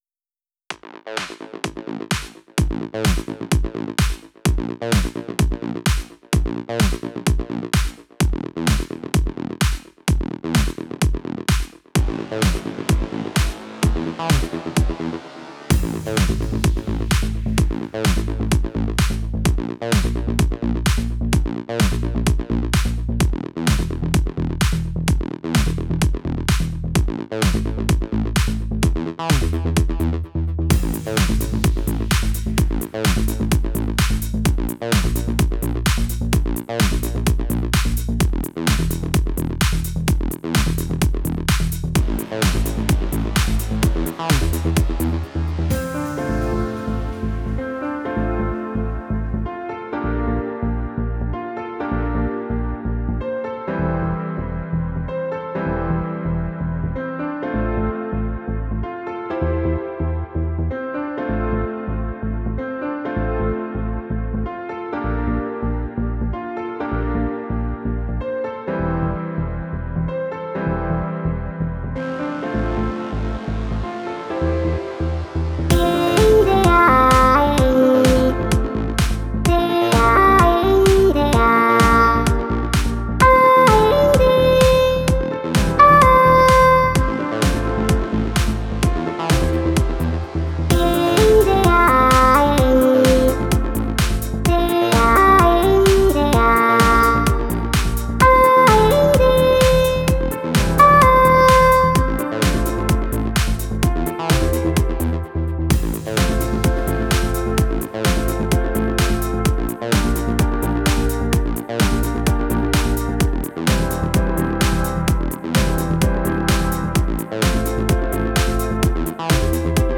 music / ELECTRO DEF